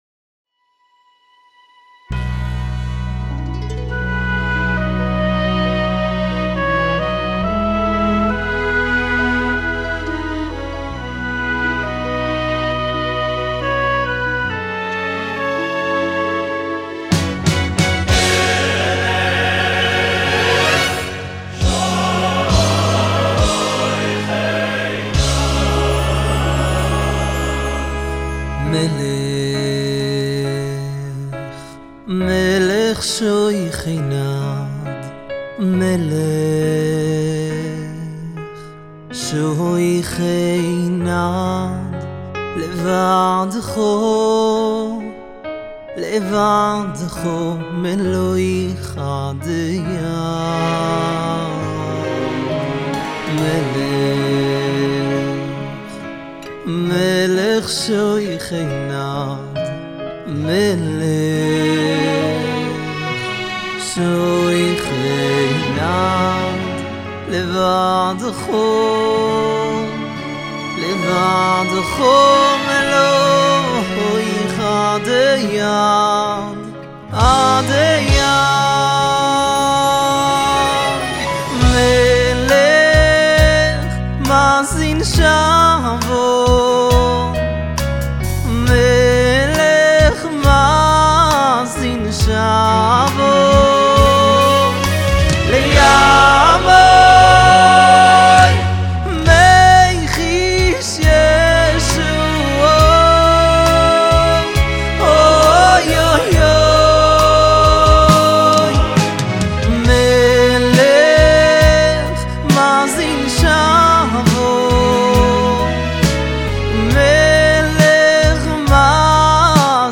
משחרר סינגל חדש וסוחף שמכניס לאוירת הימים הנוראים.